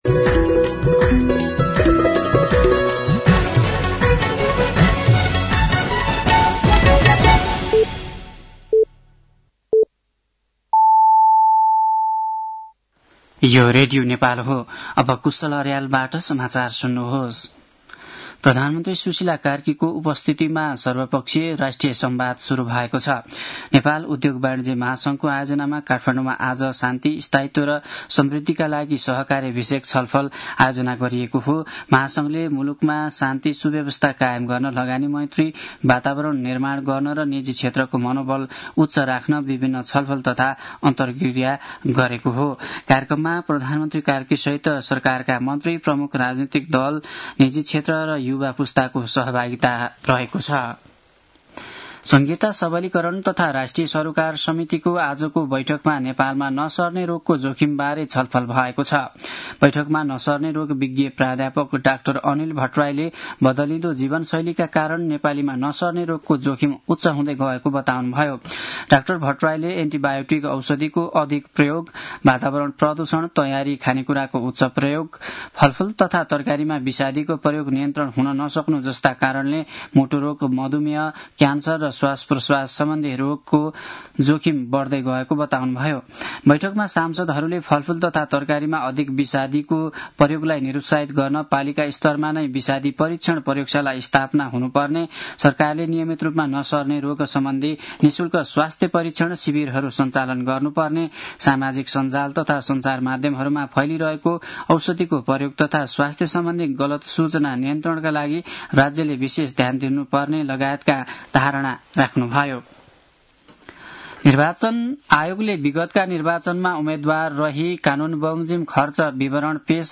साँझ ५ बजेको नेपाली समाचार : १६ पुष , २०८२